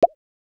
Deep Bubble Notification.wav